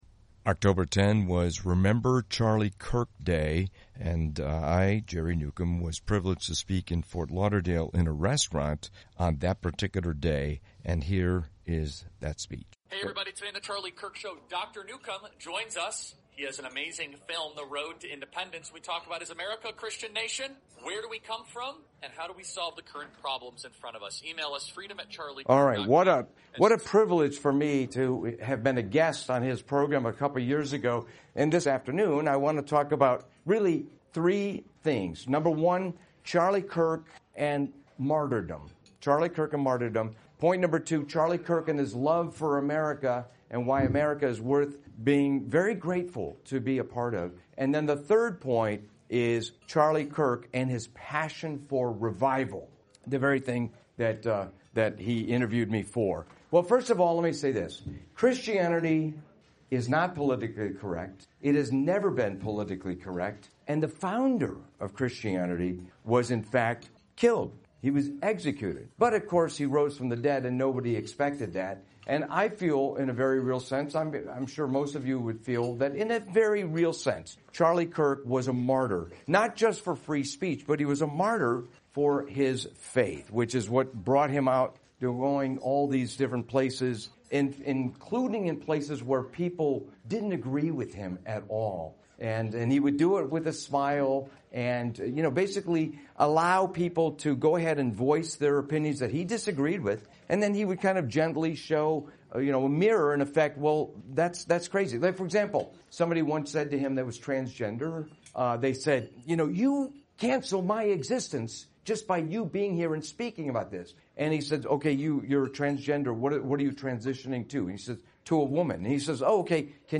Honoring Charlie Kirk at a Ft. Lauderdale Event
On October 14, 2025—on what would have been his 32nd birthday, several people came to a restaurant in Ft. Lauderdale, to honor the memory of Charlie Kirk.